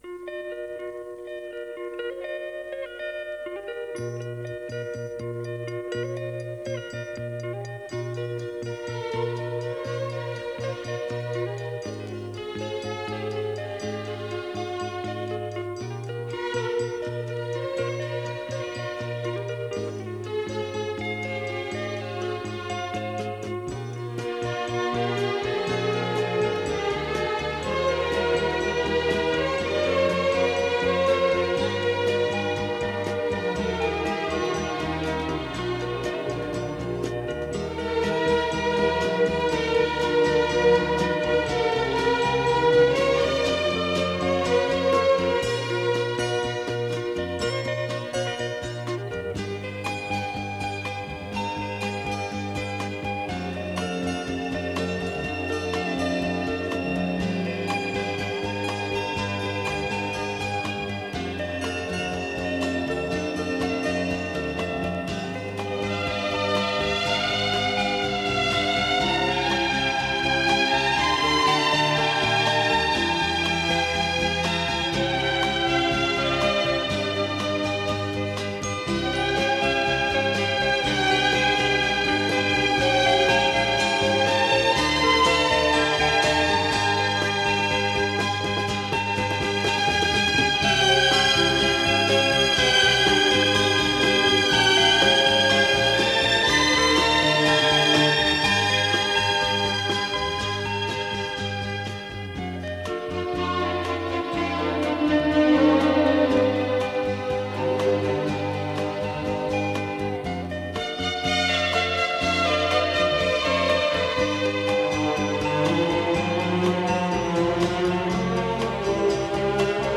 Скрипки всегда были главными «певцами» его оркестра.